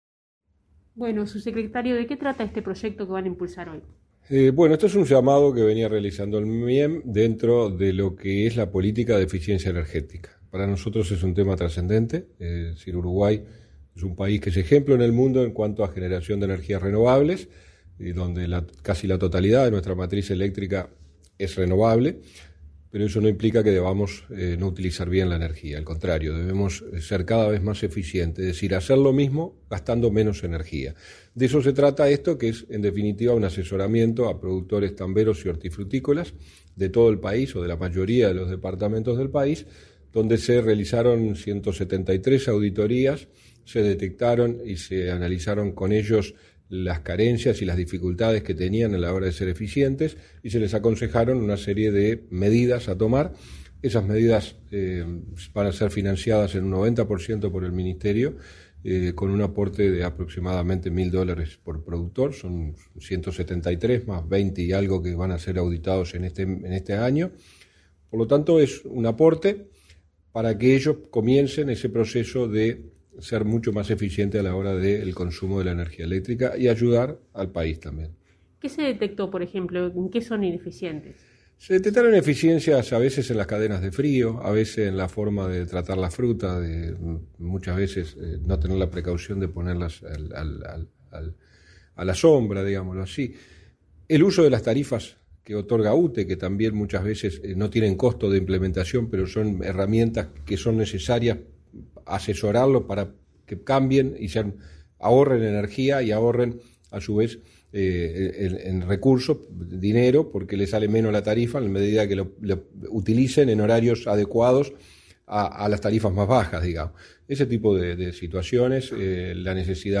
Entrevista al subsecretario del Ministerio de Industria, Energía y Minería, Walter Verri